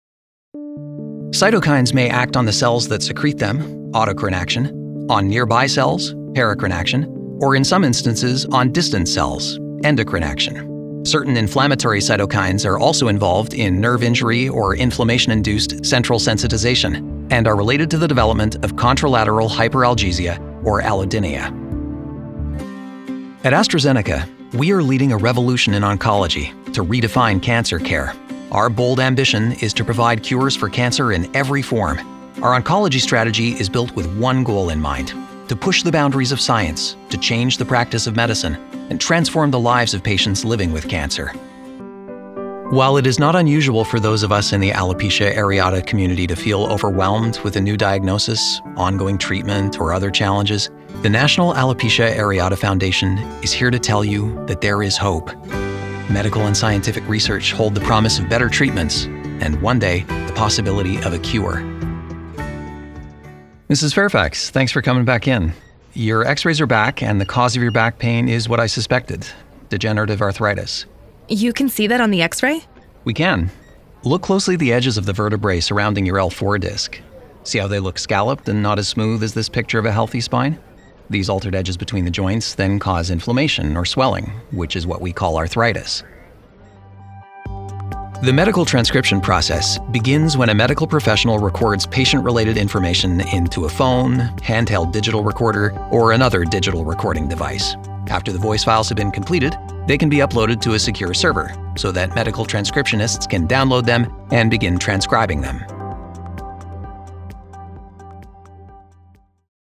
Male
American English (Native) , Canadian English (Native) , French Canadian
Approachable, Assured, Authoritative, Confident, Conversational, Corporate, Deep, Energetic, Engaging, Friendly, Funny, Gravitas, Natural, Posh, Reassuring, Sarcastic, Smooth, Soft, Upbeat, Versatile, Warm, Witty
Microphone: Sennheiser 416